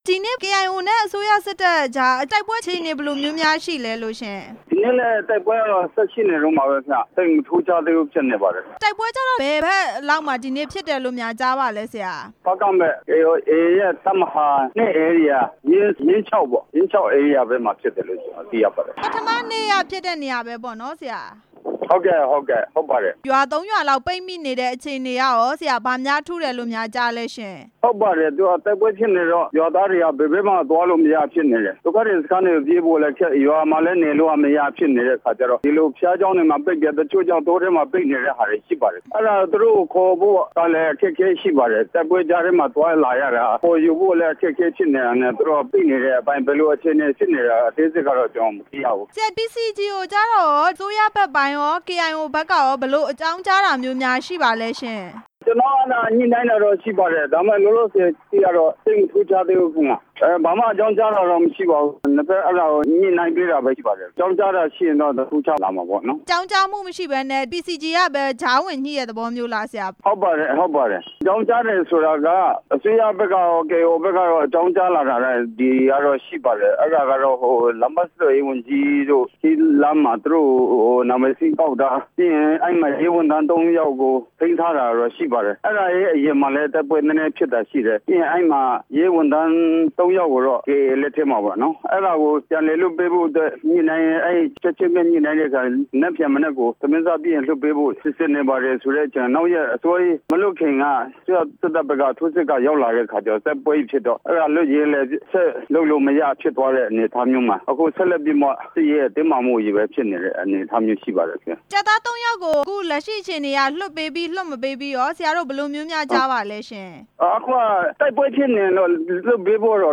ဖားကန့်မြို့နယ်က တိုက်ပွဲကြား မှာ ကျေးရွာ ၃ ရွာ ပိတ်မိနေတဲ့ အကြောင်း မေးမြန်းချက်